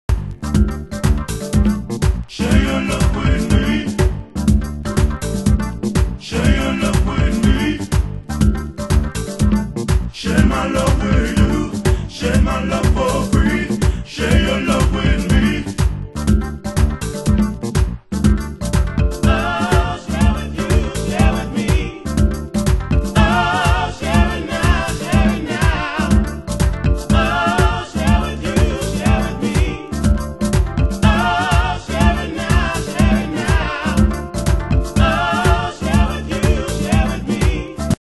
HOUSE MUSIC
伴奏の控えめなKEYが心地よい歌モノ！